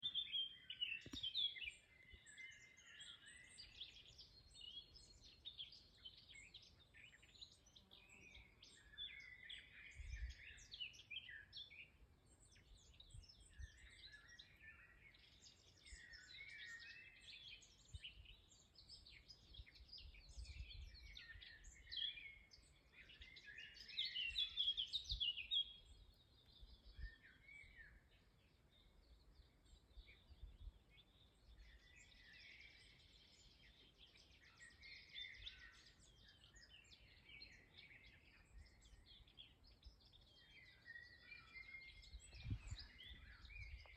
Birds -> Warblers ->
Blackcap, Sylvia atricapilla
StatusSinging male in breeding season